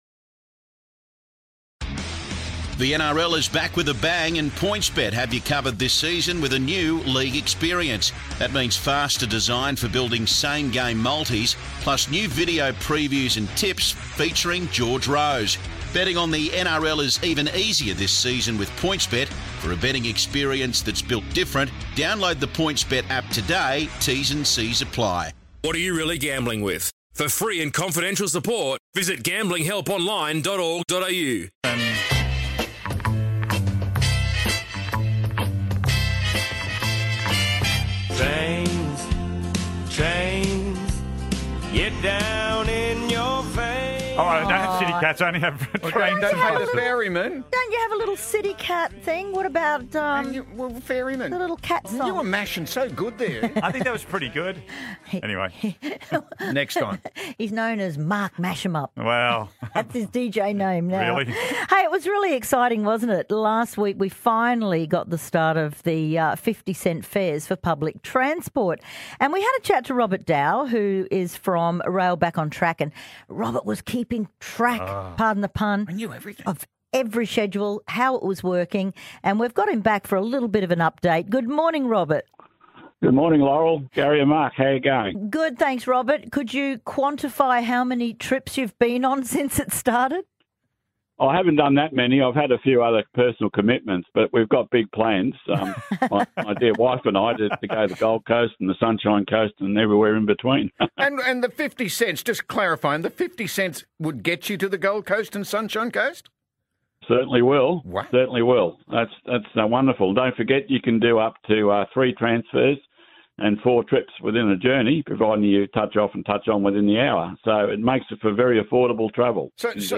Been booked for an interview on 4BC Breakfast ~ 7:10am.